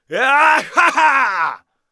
cheers2.wav